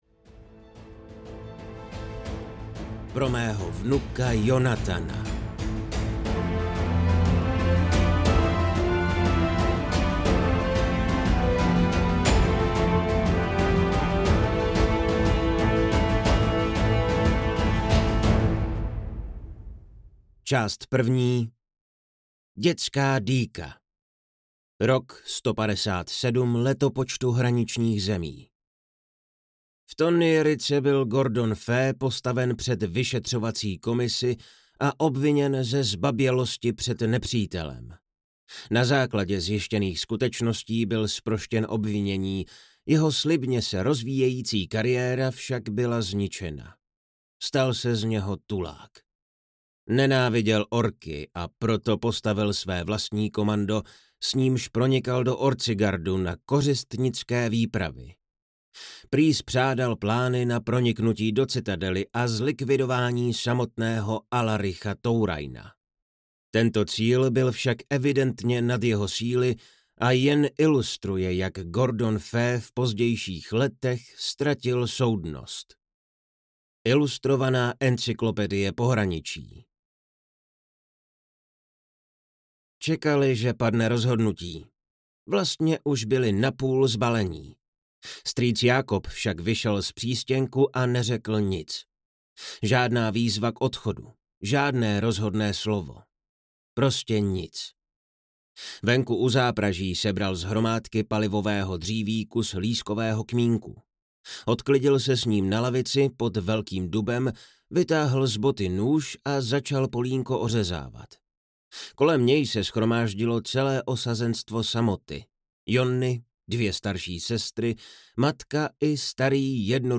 Hraničář audiokniha
Ukázka z knihy